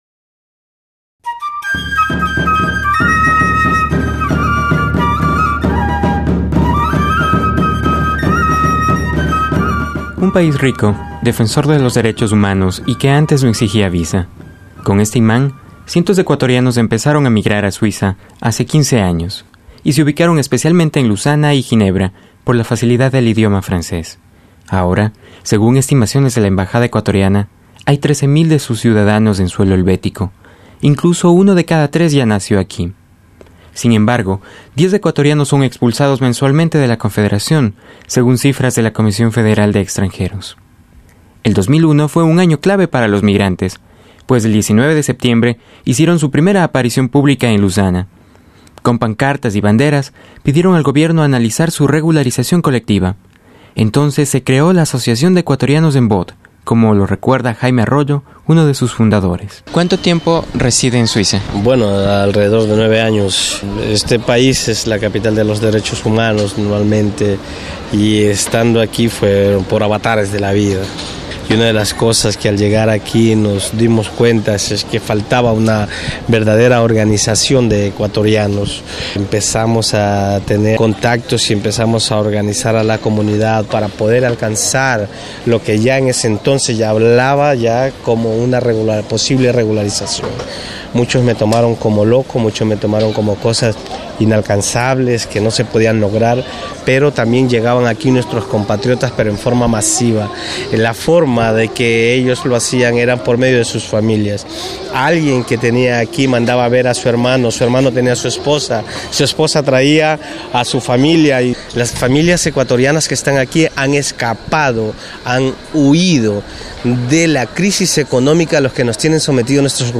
La inmigración ecuatoriana empezó hace 15 años. Hoy son 13.000 y casi un 30 por ciento de esta comunidad nació en suelo helvético. Algunos tienen pequeñas empresas, otros trabajan en actividades domésticas y de servicios. Reportaje